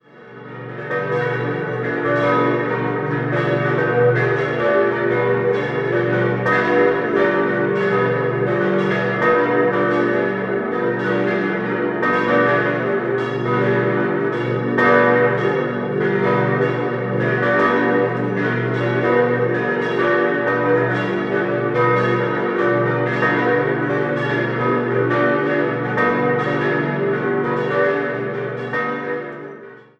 Erbaut wurde sie in den Jahren 1881 bis 1885 nach den Plänen des Wiener Dombaumeisters Friedrich von Schmidt. 6-stimmiges Geläut: h°-d'-e'-fis'-gis'-ais' Die Glocken wurden im Jahr 1931 von Friedrich Hamm in Salzburg gegossen.